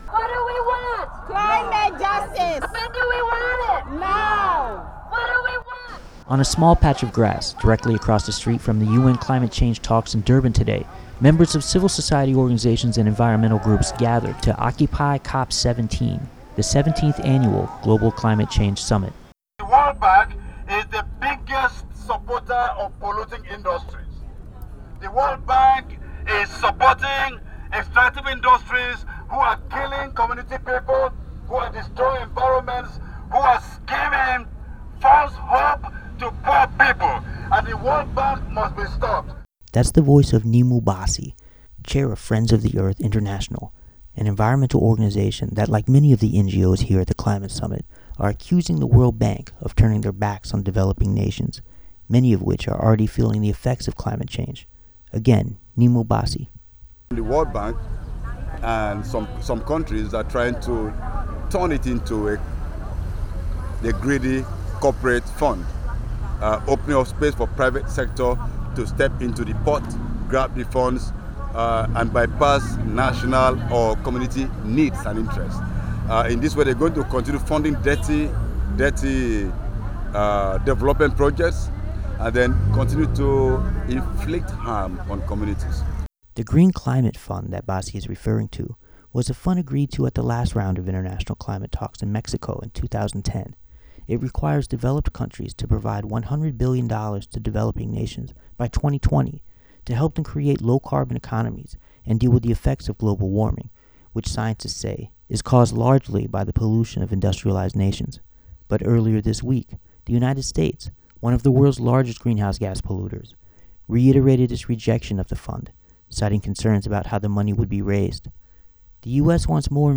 United Nations Climate Change Conference Coverage: Nov 30, 2011 - Dec 11, 2011